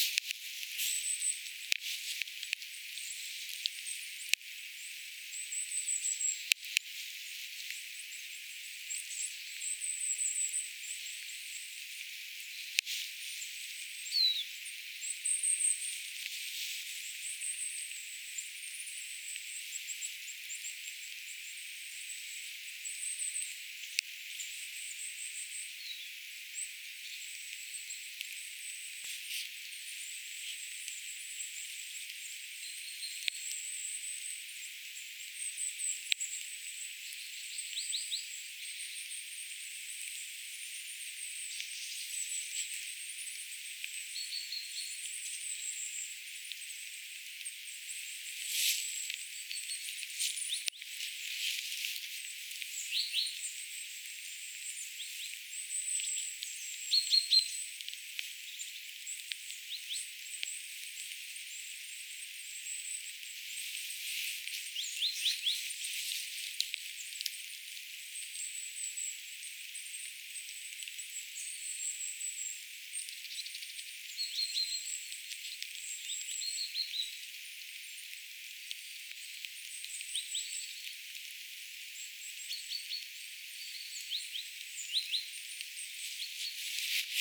hippiäisiä saaressa
hippiaisia_saaressa.mp3